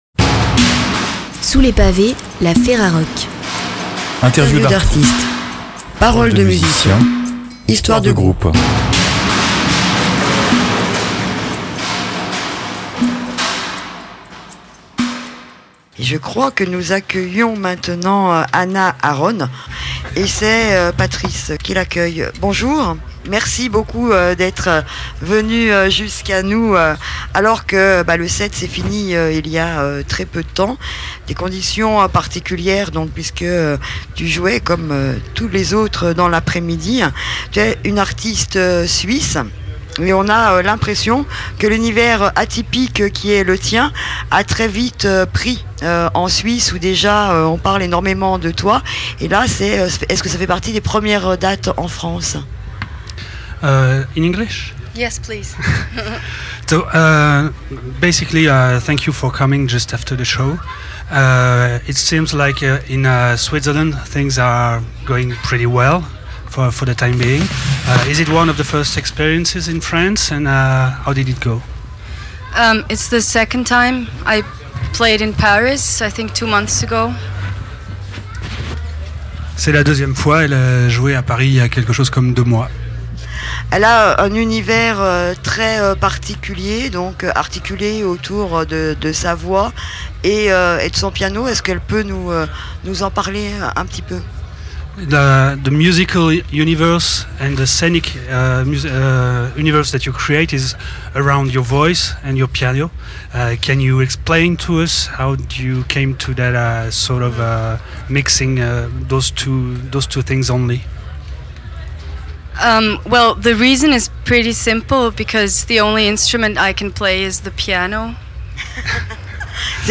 Vous êtes l'artiste ou le groupe interviewé et vous souhaitez le retrait de cet interview ? Cliquez ici.